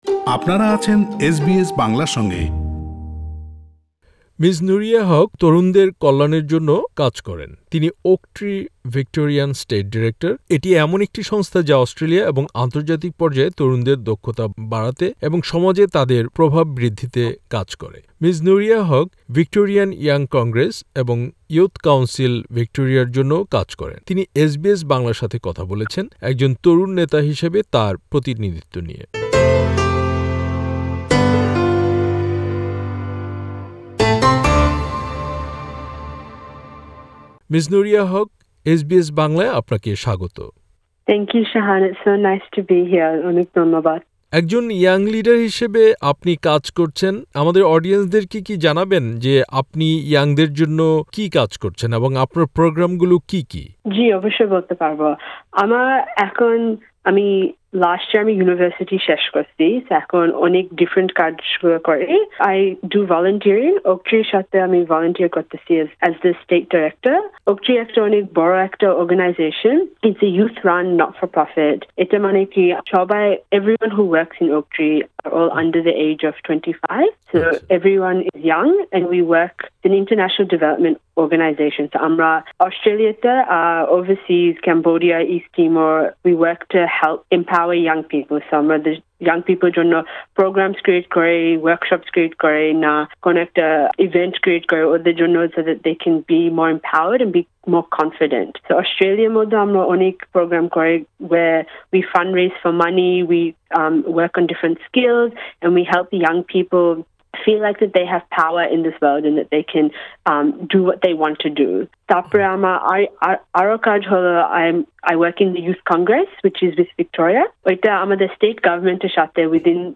সাক্ষাতকারটি